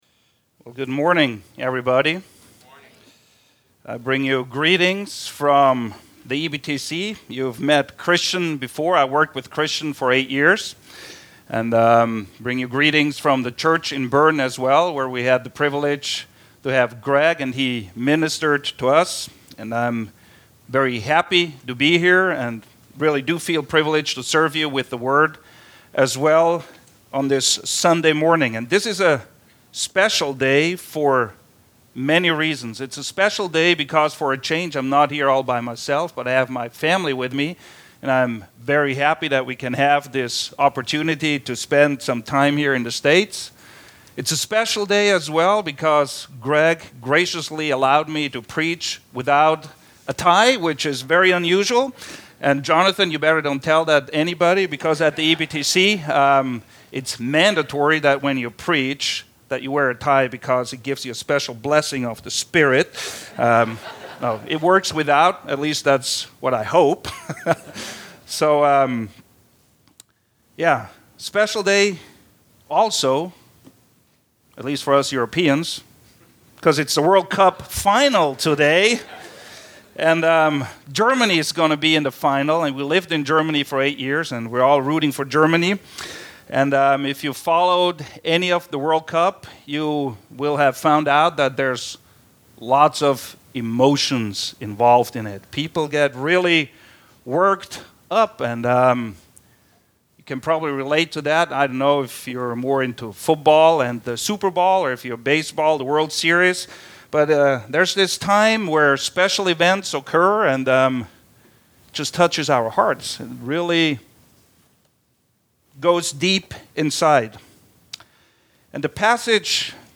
The Foundation of Happiness Guest Preacher